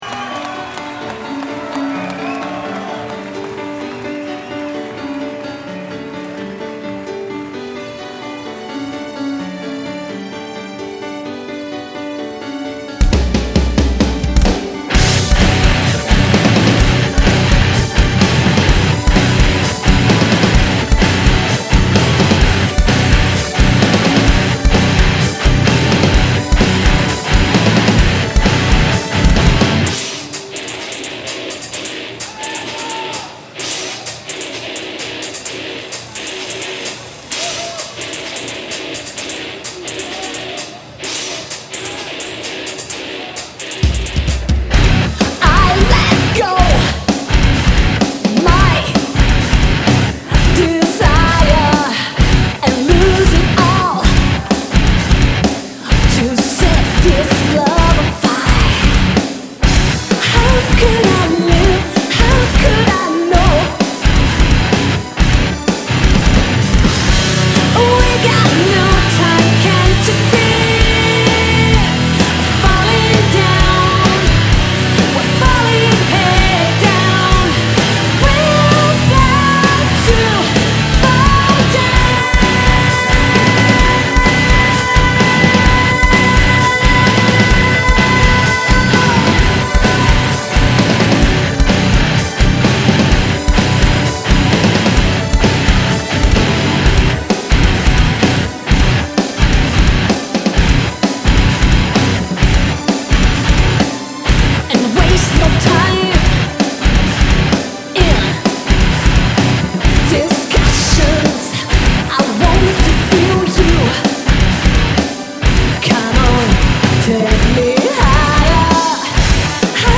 Vocals
Lead Guitars
Drums
Rythm Guitars
bass and samples
Recorded Live